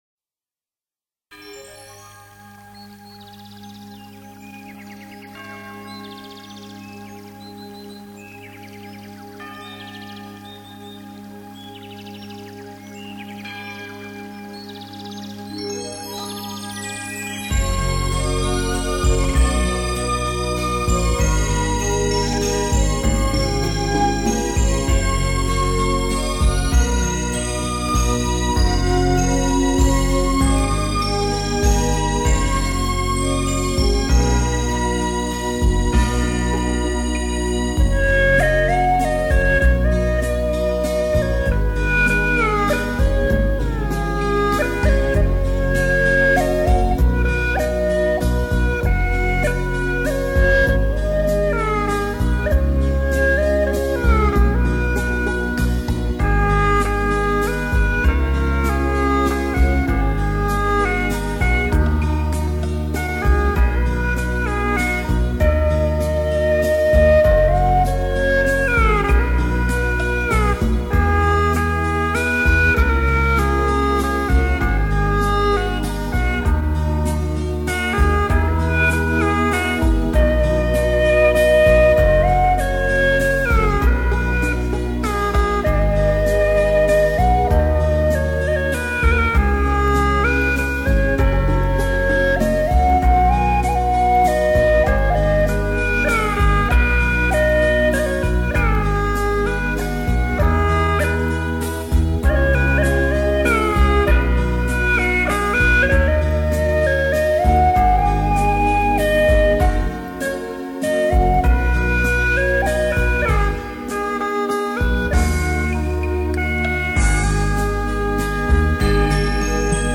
葫芦丝--葫芦为音箱，音色柔美动人......
葫芦丝的音色柔美，常用于独奏或合奏。